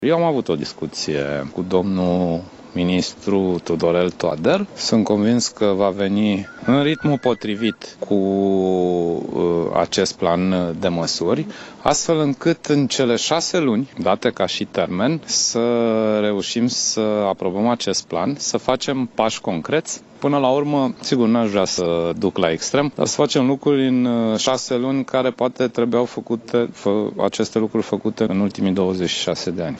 După decizia de astăzi a CEDO, referitoare la condițiile din penitenciare, premierul Sorin Grindeanu a declarat că a purtat o discuției cu ministrul Justiției, Tudorel Toader pe această temă.
25apr-13-grindeanu-reactie-CEDO.mp3